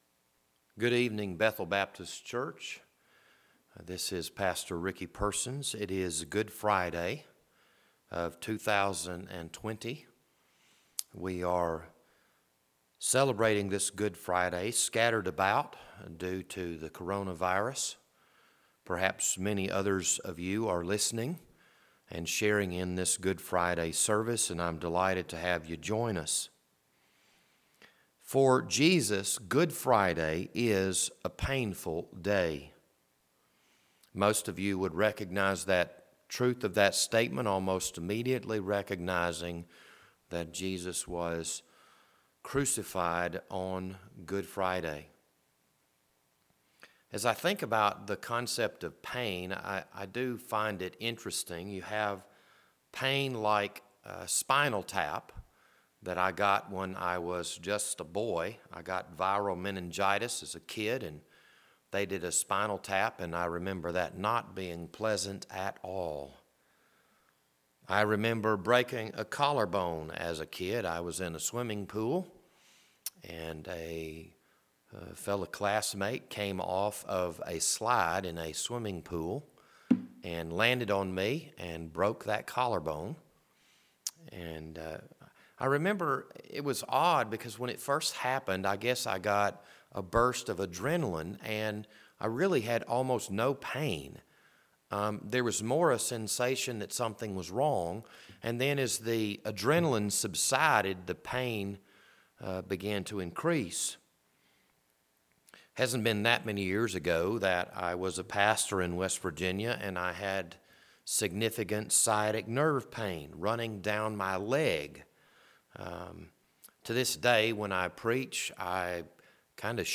This sermon was recorded for April 3rd, 2026, Good Friday.